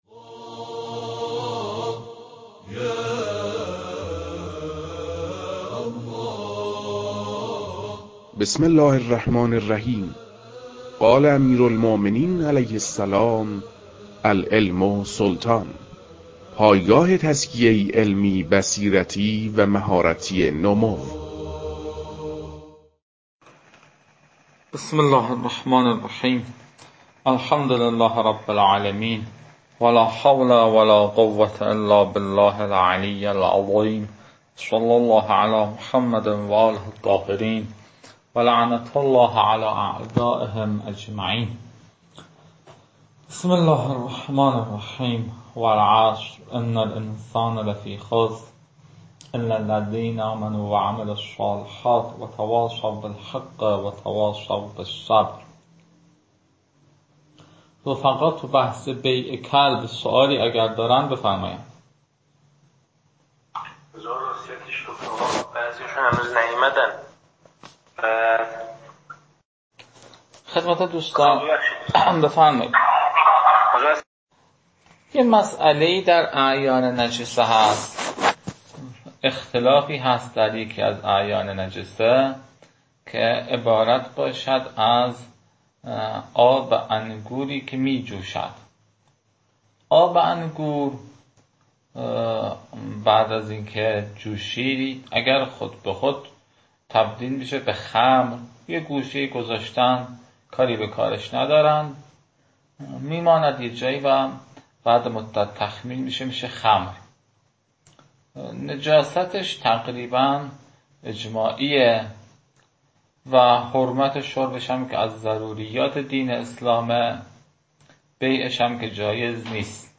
تدریس بخش نخست كتاب المكاسب متعلق به شیخ اعظم انصاری رحمه الله (مکاسب محرّمه)